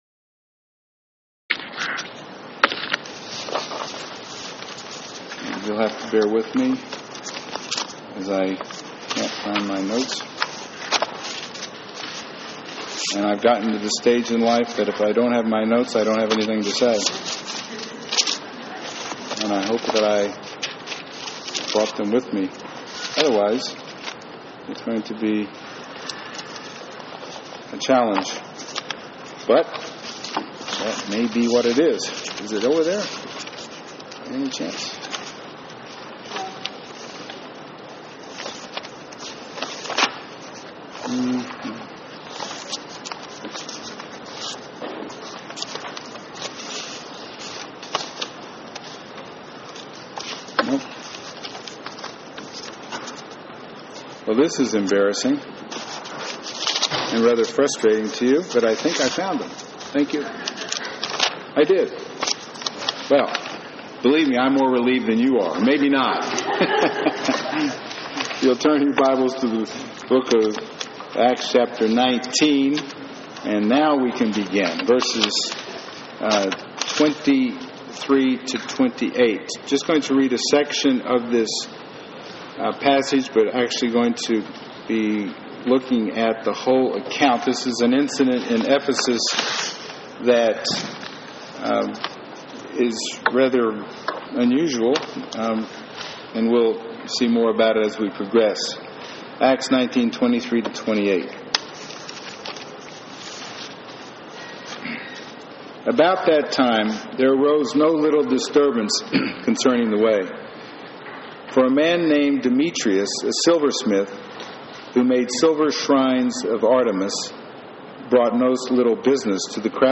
Preacher : Guest Speaker Passage: Acts 17:16-21 Service Type: Sunday Morning